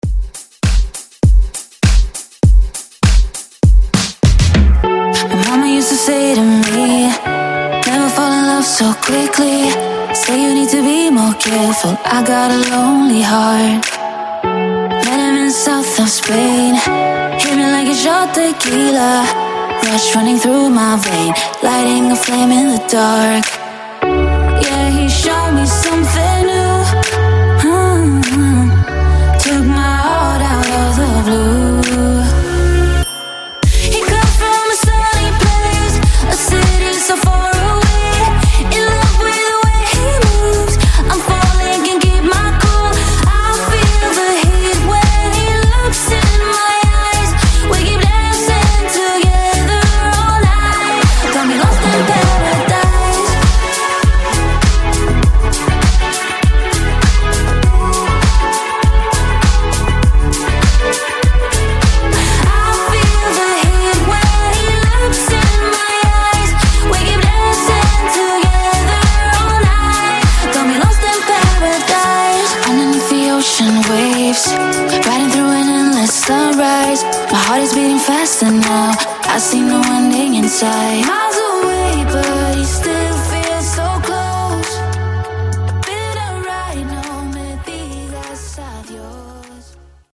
Genre: 90's
Clean BPM: 123 Time